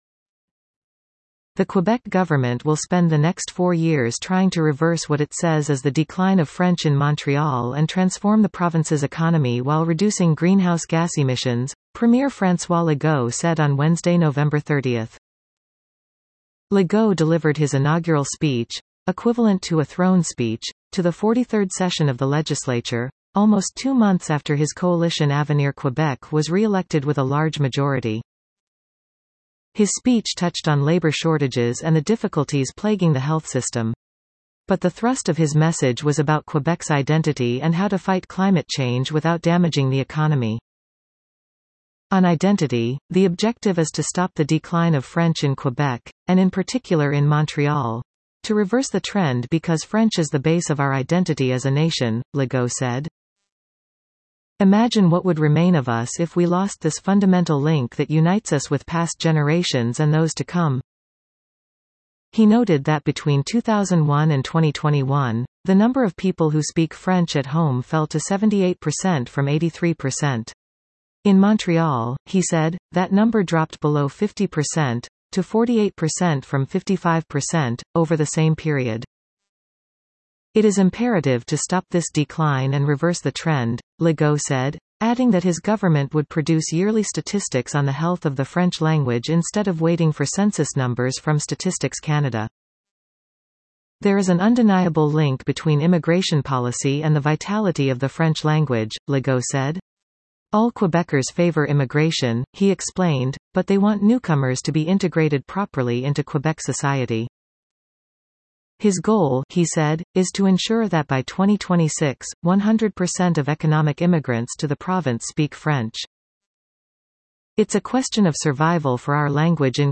Economy and identity: Quebec premier delivers inaugural speech of 43rd legislature